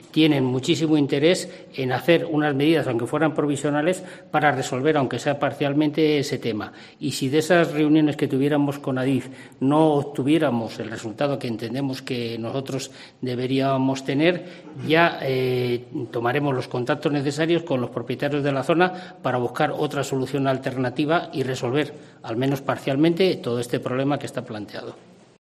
Alejandro González-Salamanca, concejal de Urbanismo de Segovia